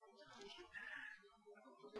Flüstern (Bearbeitet)